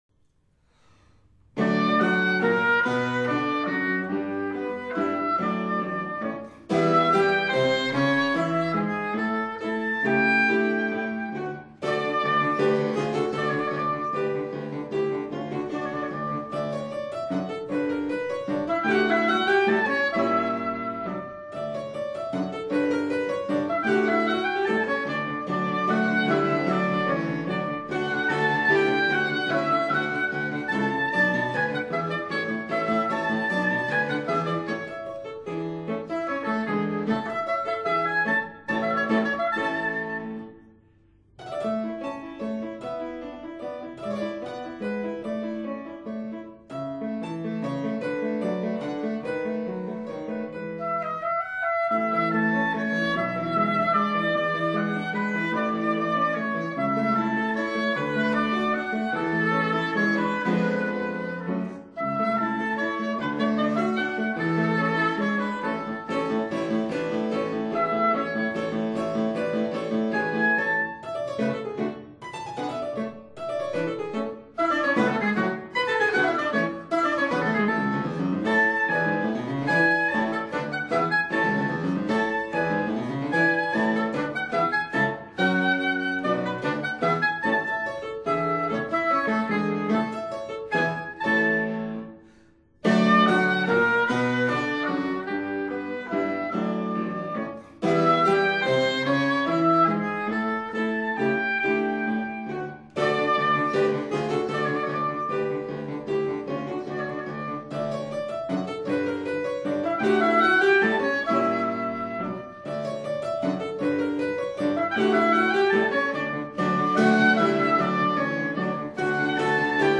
Concerto for oboe & orchestr No. 7 in F major- Allegro